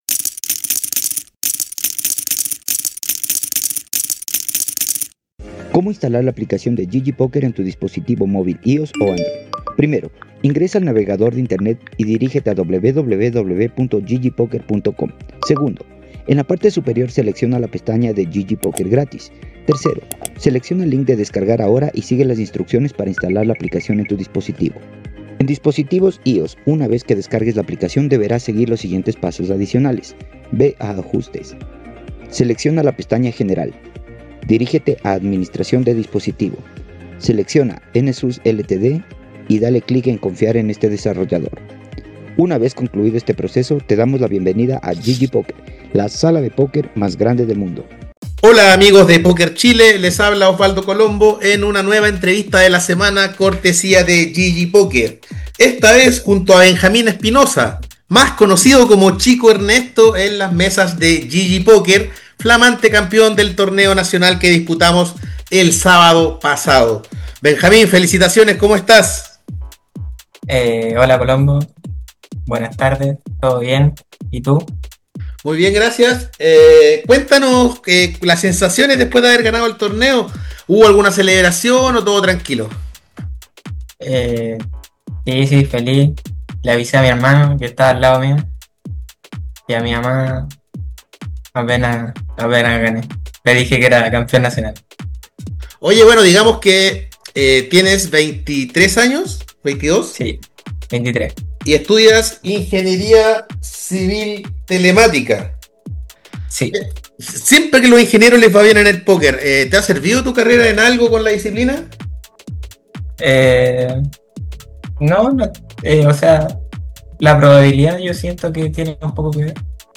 Finalmente, habló de sus metas para el 2026 y sus próximos pasos en la disciplina. Puedes escuchar la entrevista completa a continuación: